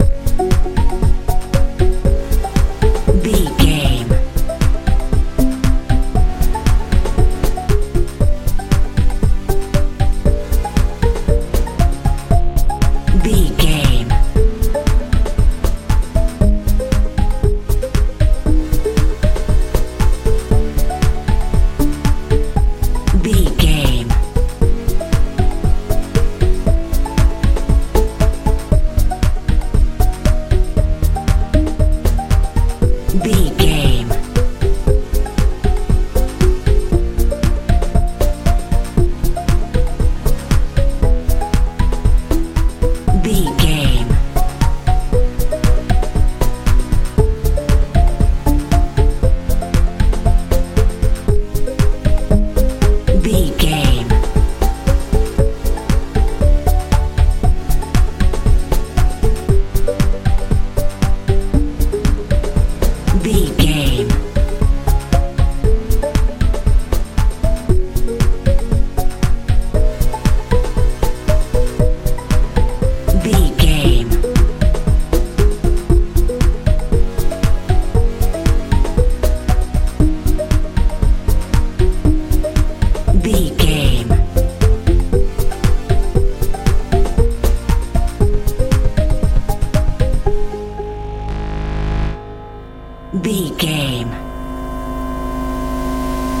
modern pop feel
Ionian/Major
F♯
groovy
funky
electric guitar
bass guitar
drums
80s
90s
strange
high tech